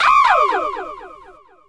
VOX SHORTS-1 0021.wav